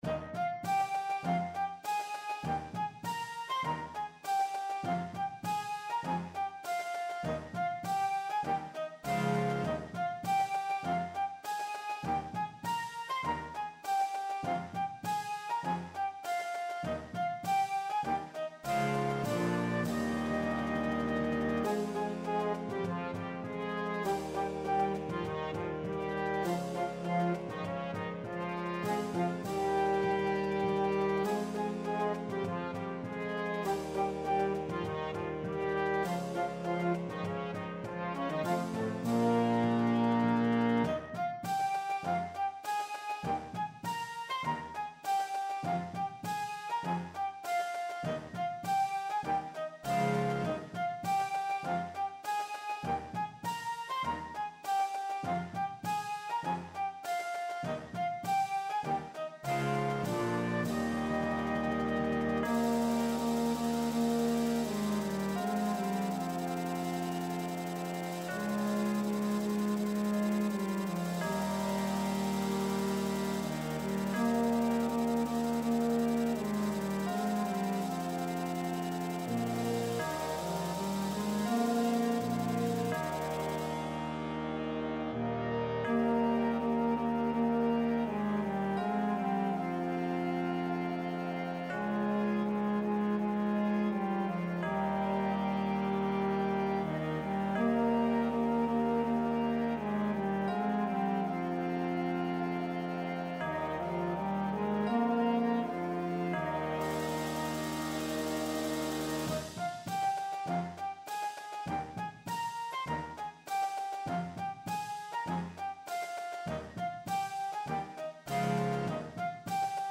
is a march written in a modified ABA form.